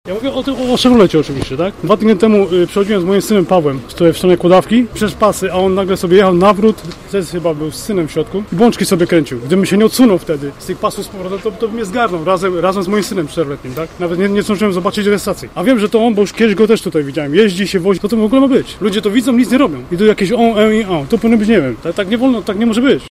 Okoliczni mieszkańcy mówią naszemu reporterowi, że kierowca Chevroleta pozwalał sobie już wcześniej na niebezpieczną jazdę w tych okolicach.